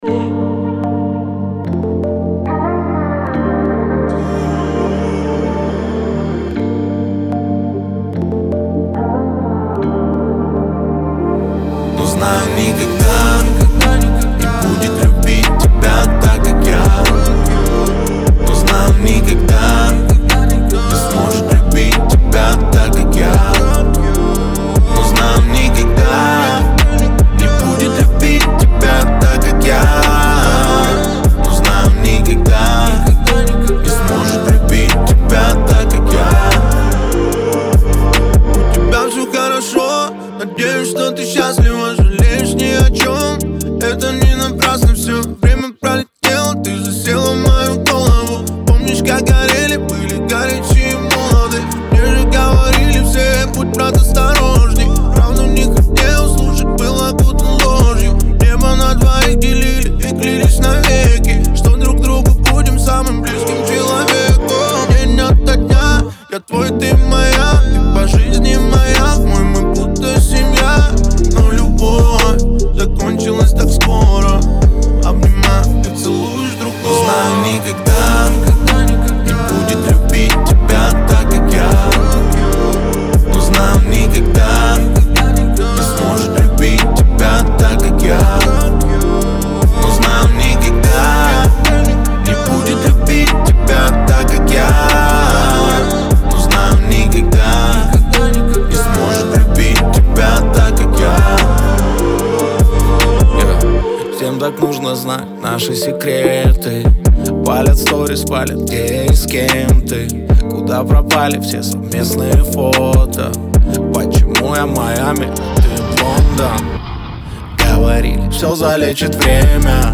это современный поп-трек с элементами R&B